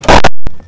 ShadowJumpscareSound.mp3